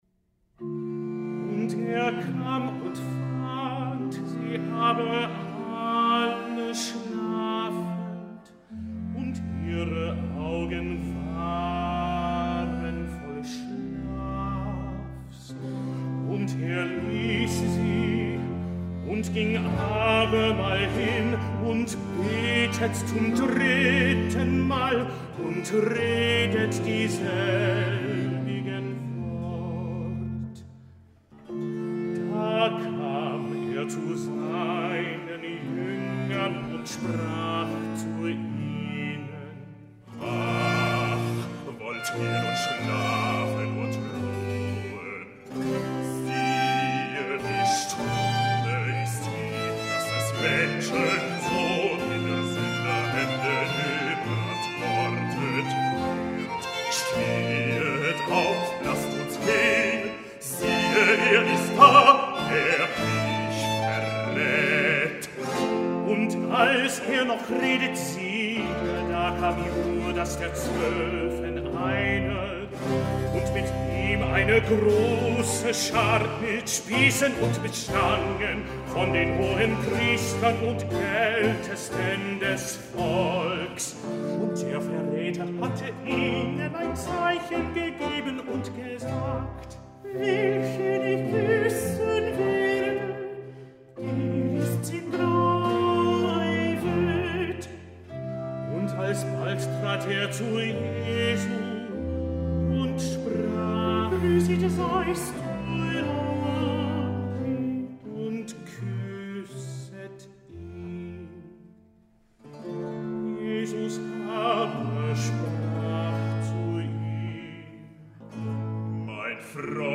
Sa passion selon Saint Matthieu est remarquable, non seulement pour la beauté lyrique de sa musique et sa riche instrumentation (violes pour l’évangéliste, violons pour Jésus) mais également pour l’introduction de chorals luthériens à des moments stratégiques tout au long du récit.
timbre somptueux, déclamation majestueusement noble et virile. L’impression d’ensemble est, malgré  l’austérité dramatique du propos, d’une certaine sérénité et d’un grand équilibre musical (déroulé fluide et très « chantant », moins contrasté que d’autres passions plus nettement découpées en airs et récitatifs caractérisés).